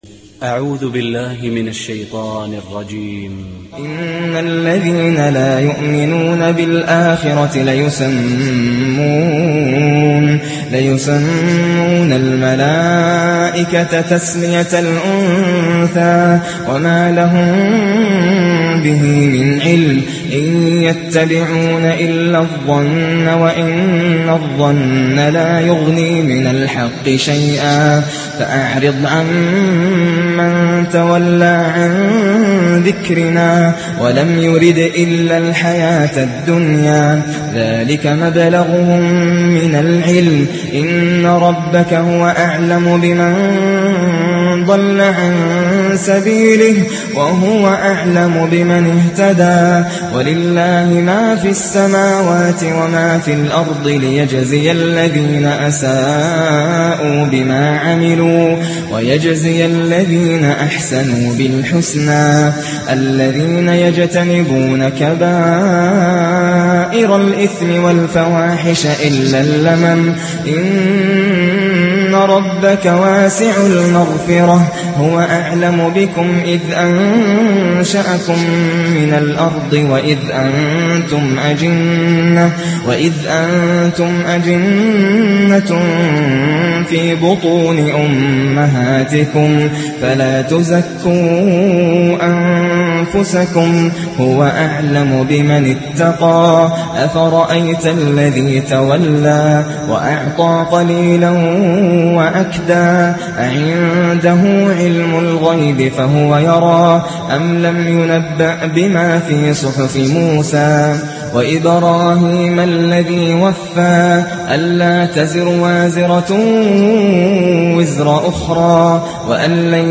بحق تعجبني تلاوة القارئ ناصر القطامي
فعلا قراءة هذا القارئ لها وقع على القلب
فعلاً ناصر القطامي ذو صوت شجي ......